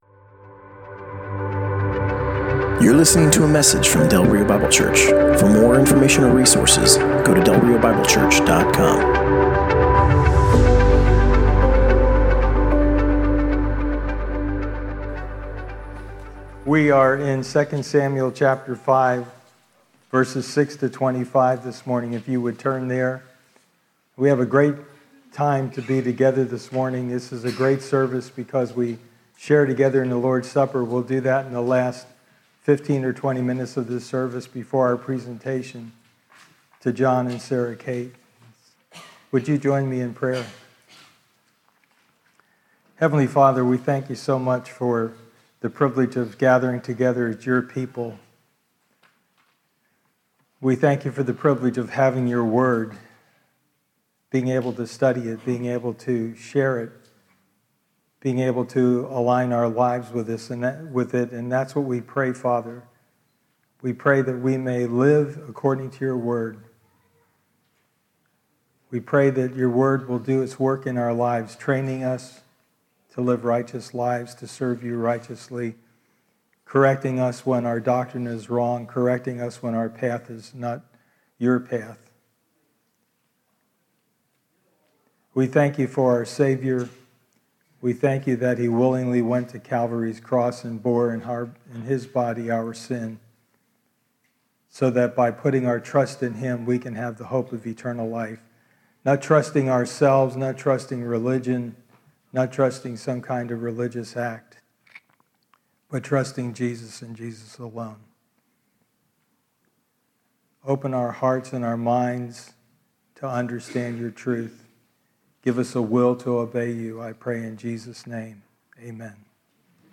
Passage: 2 Samuel 5: 6-25 Service Type: Sunday Morning